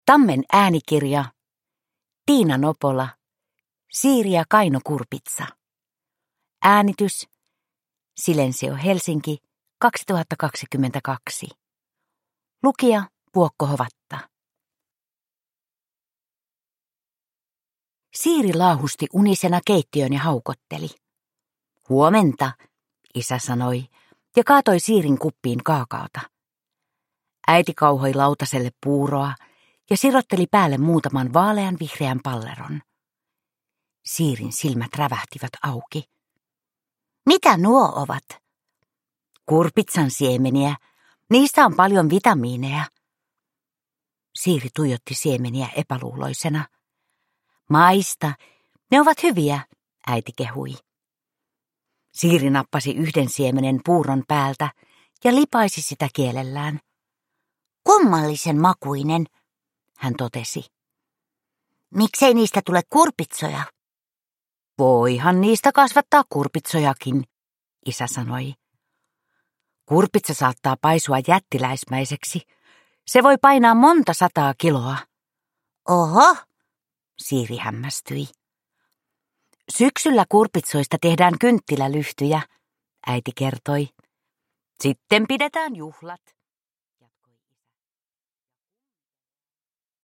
Siiri ja kaino kurpitsa – Ljudbok